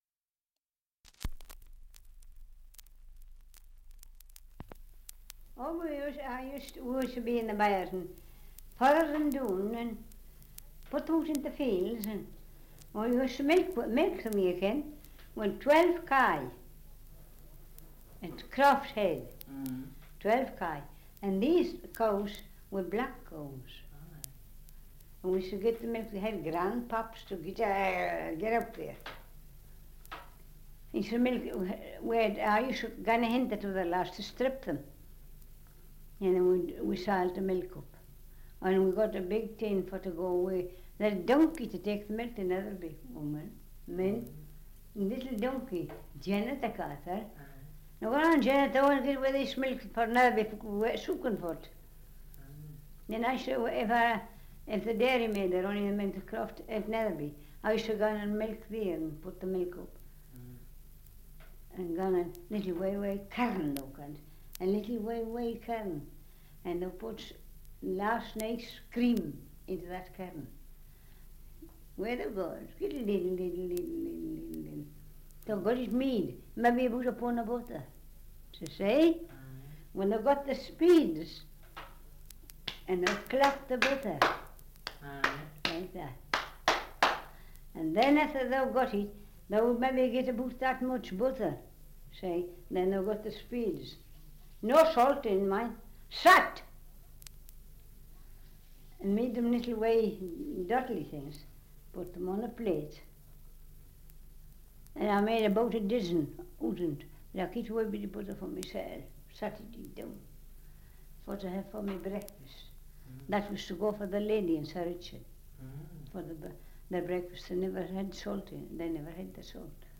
Survey of English Dialects recording in Longtown, Cumberland
78 r.p.m., cellulose nitrate on aluminium